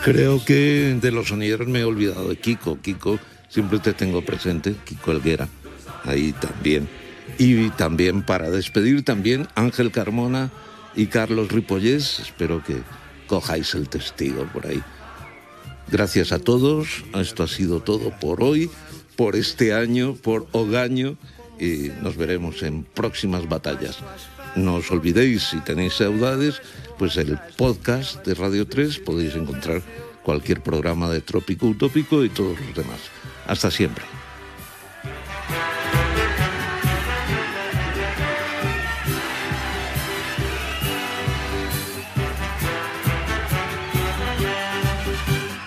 Fragments del darrer programa després de 42 anys en antena.
Musical